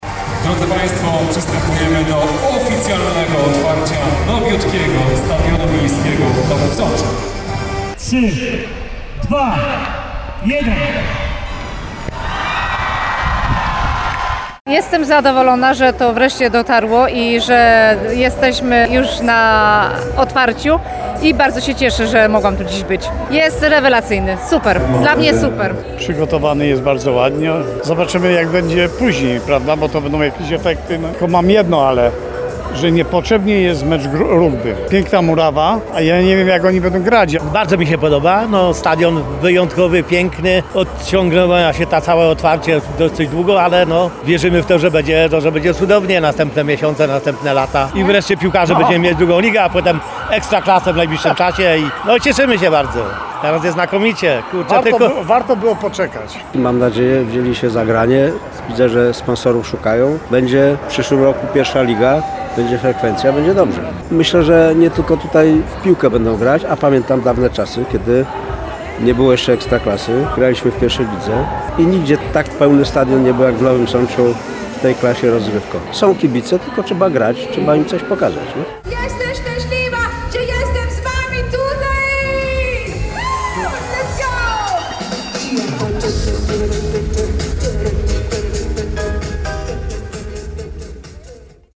Warto było poczekać – mówią uczestnicy inauguracji.